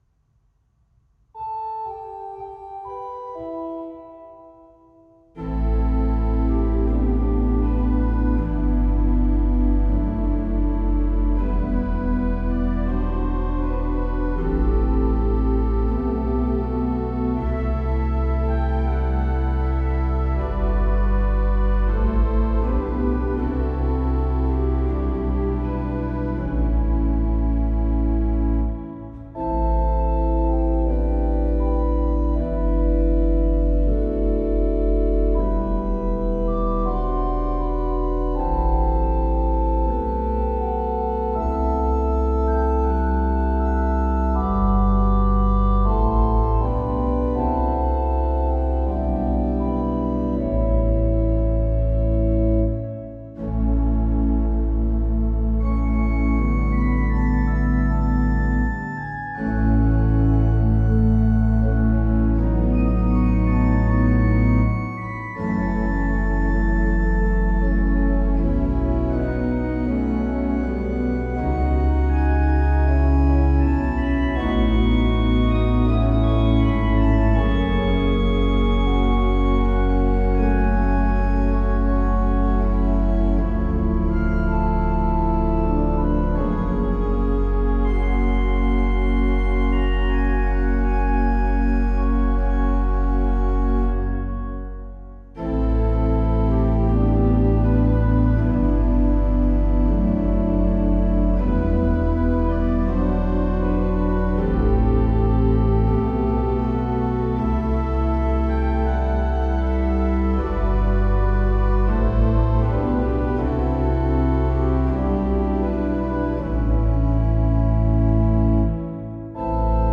Orgel (2024)